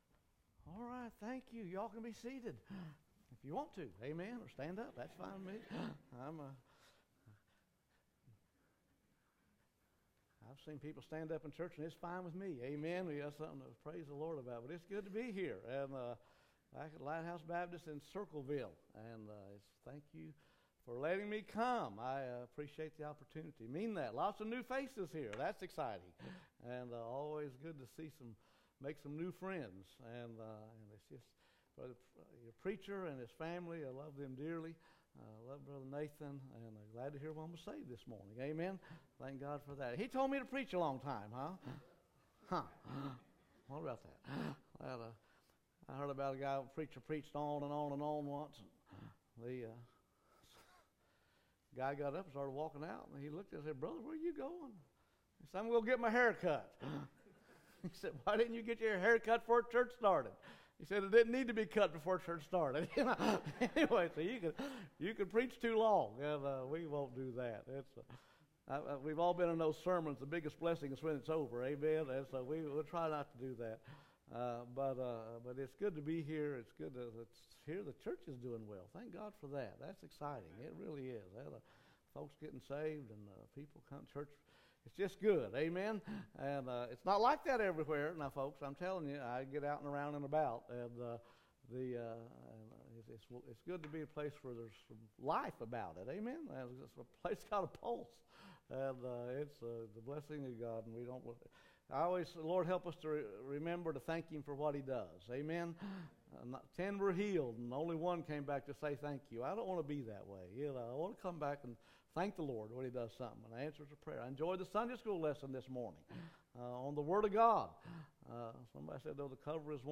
Guest speaker
Sunday morning, February 6, 2022.